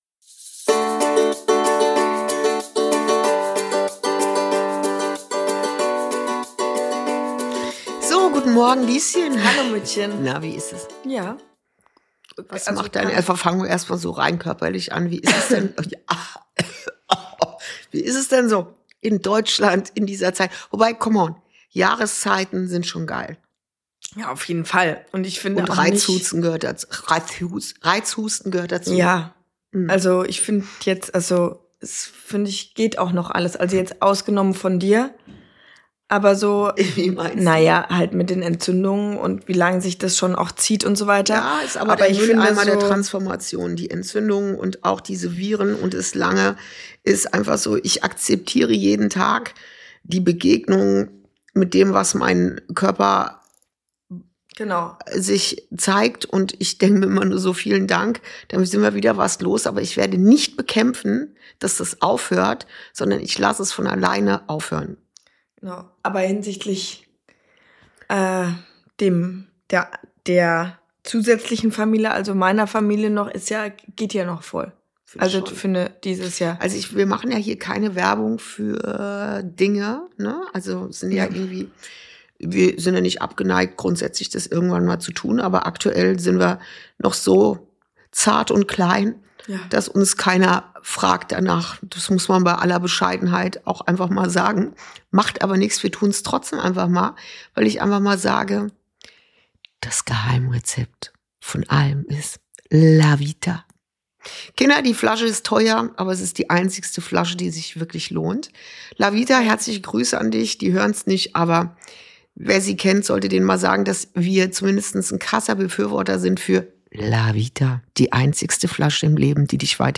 Folge 40: Wahrnehmen statt bewerten – der Weg vom Schein zum Sein ~ Inside Out - Ein Gespräch zwischen Mutter und Tochter Podcast